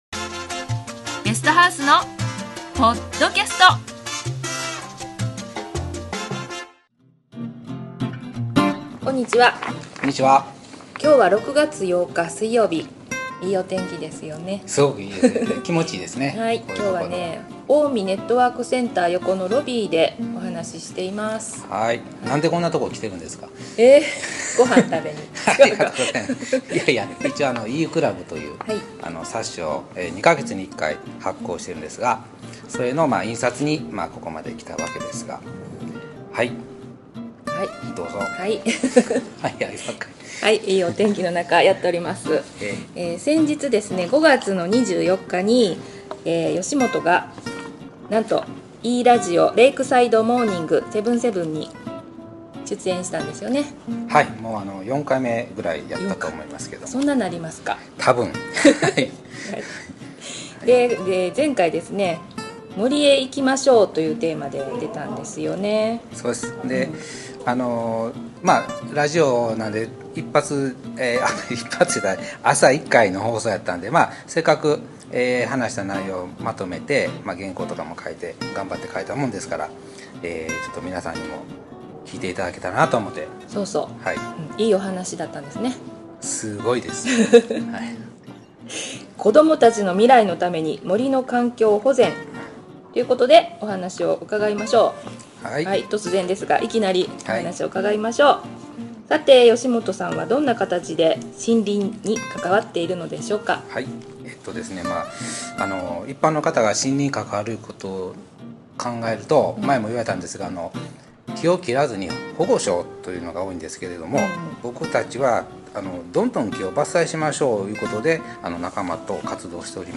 5月に出演させていただいた、Eラジオ・レイクサイドモーニング77